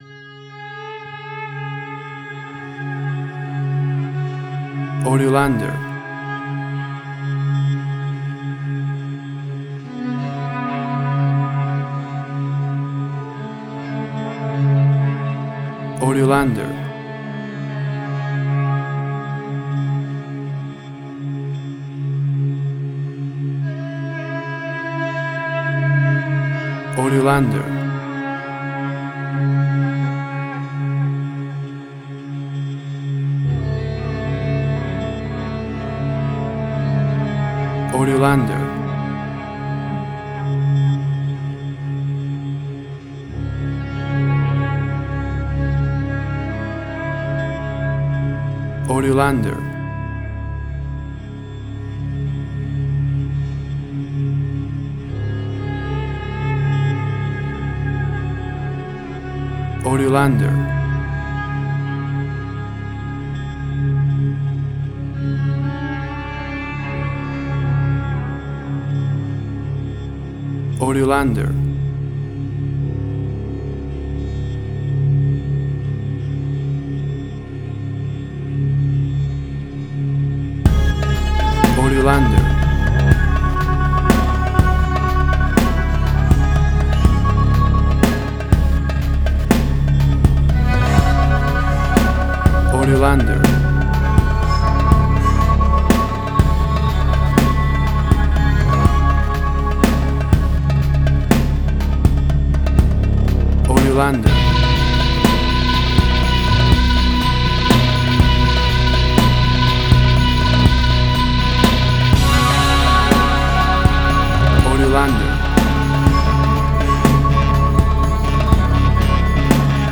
Suspense, Drama, Quirky, Emotional.
Tempo (BPM): 122